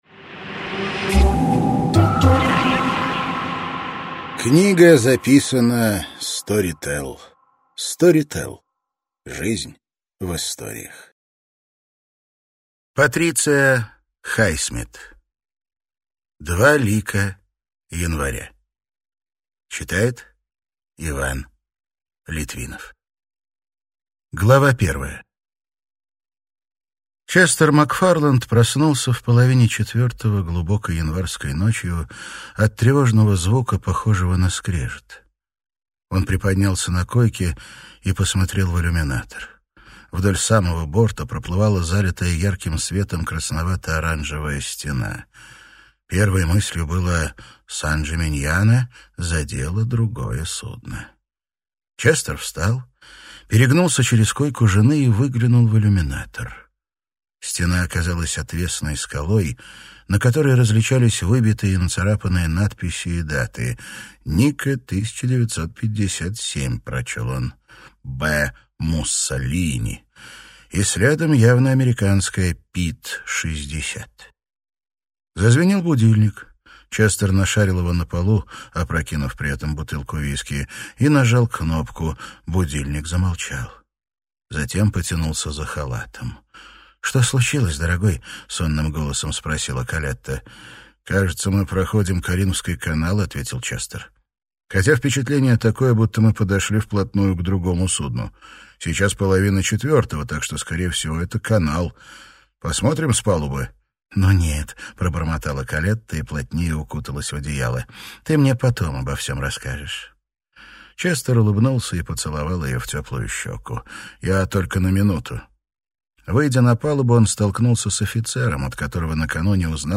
Аудиокнига Два лика января | Библиотека аудиокниг